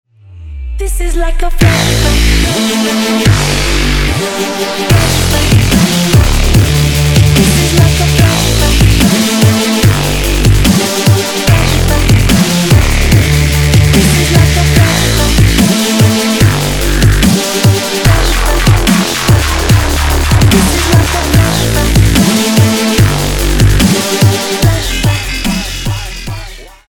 Категория : Клаб (реалтоны)